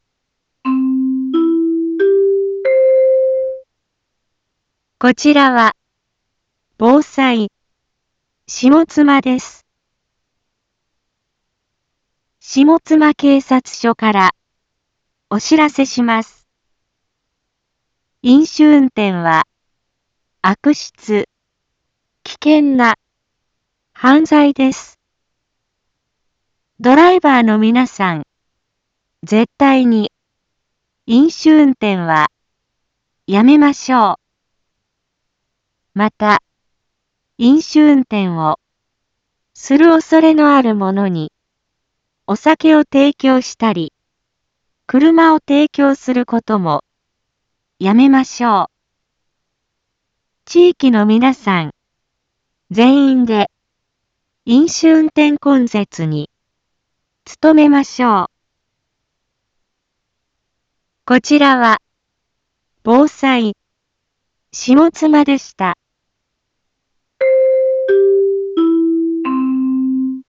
一般放送情報
Back Home 一般放送情報 音声放送 再生 一般放送情報 登録日時：2024-07-19 18:16:14 タイトル：飲酒運転根絶について インフォメーション：こちらは、ぼうさい、しもつまです。